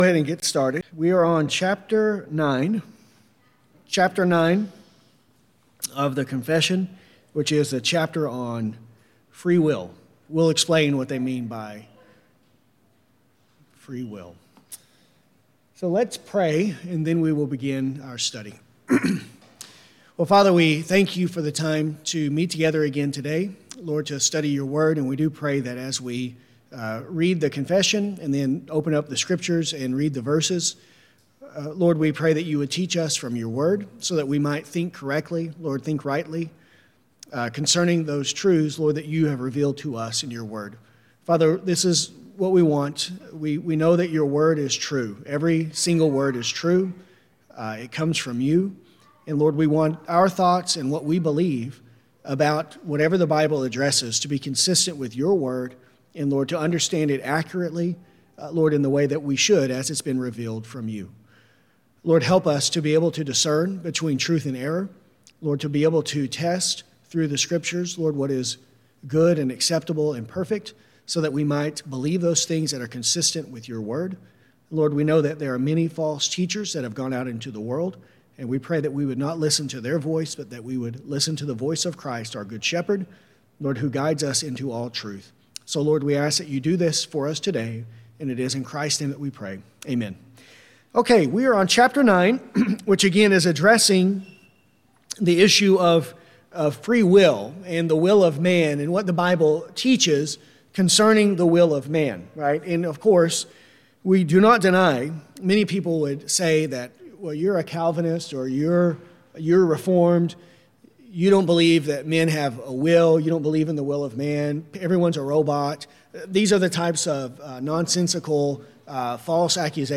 Man, by his fall into a state of sin, hath wholly lost all ability of will to any spiritual good accompanying salvation This lesson covers Paragraphs 9.1-9.3. To follow along while listening, use the link below to view a copy of the confession.